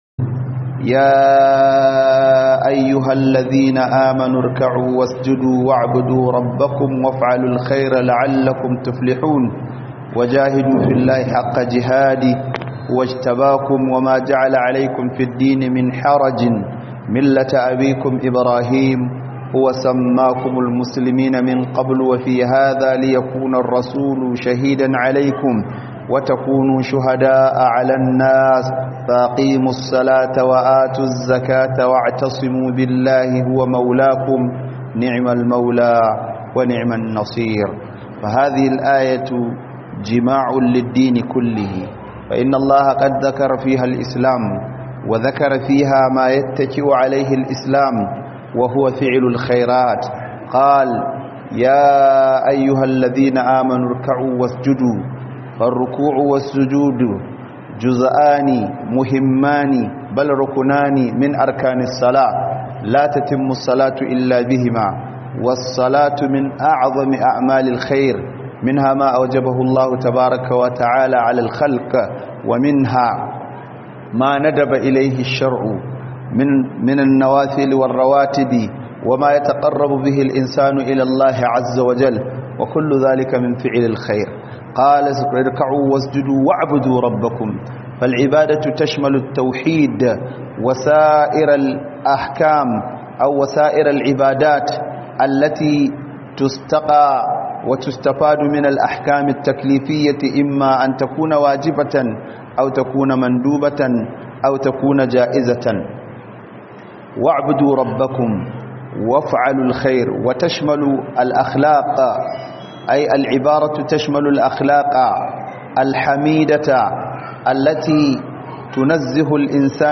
KU AIKATA AYYUKAN ALKHAIRI - Khuduba